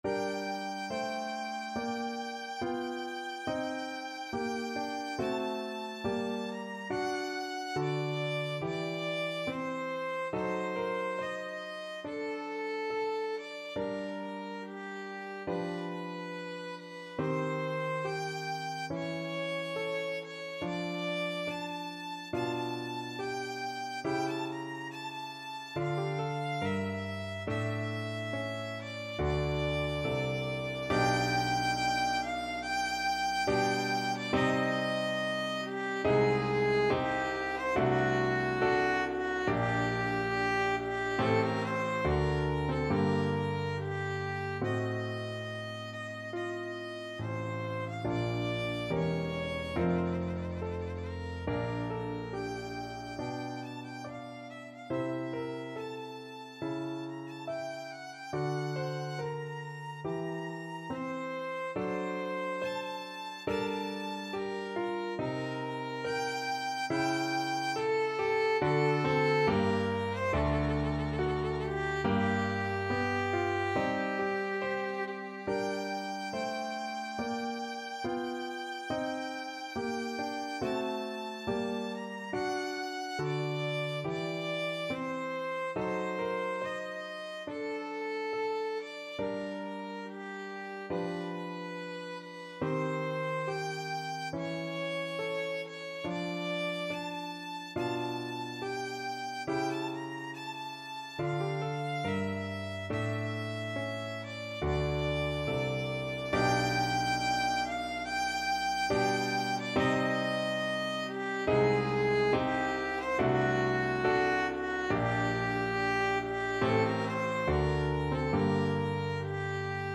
Free Sheet music for Violin
Violin
G minor (Sounding Pitch) (View more G minor Music for Violin )
4/4 (View more 4/4 Music)
Adagio =70
Classical (View more Classical Violin Music)